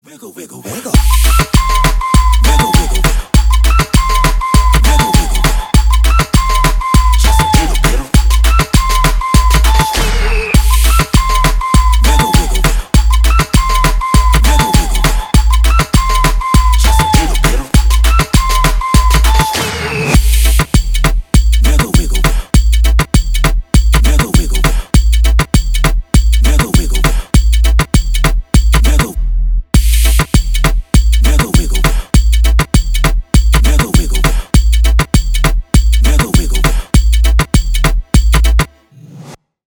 Ремикс # Рэп и Хип Хоп
без слов